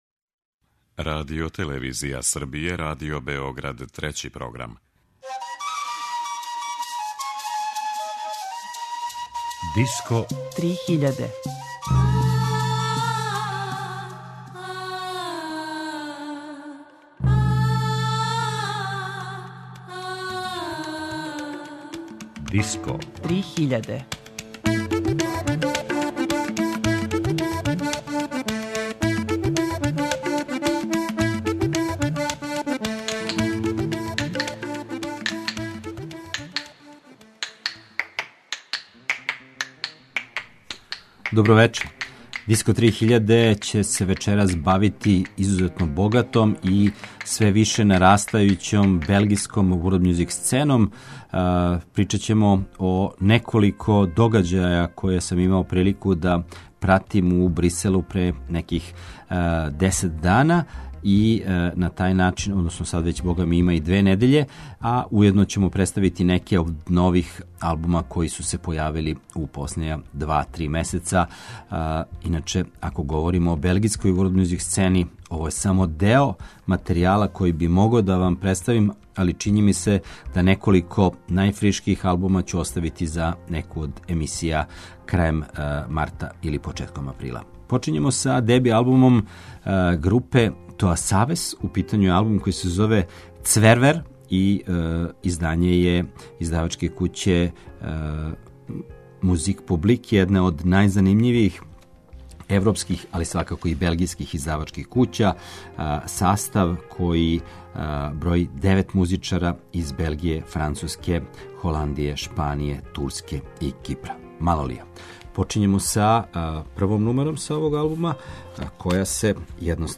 Са белгијске world music сцене